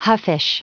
Prononciation du mot huffish en anglais (fichier audio)